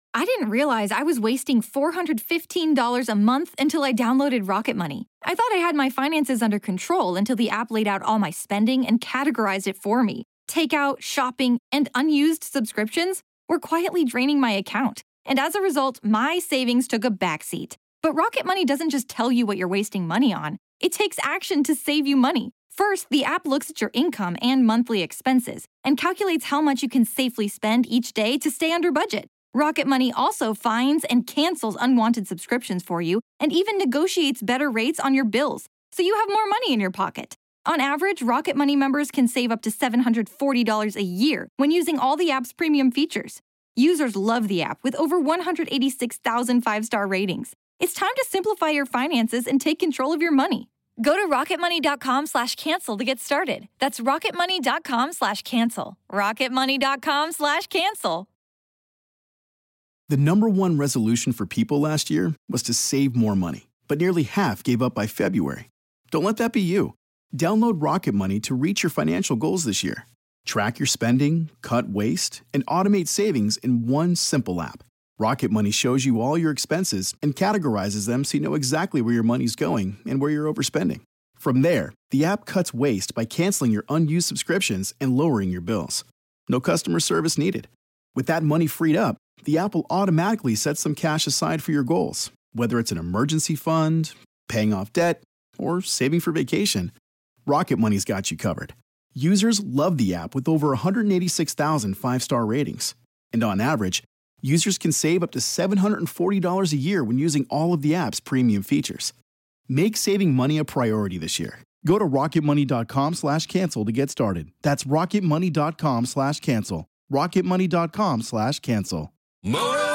a horror movie review podcast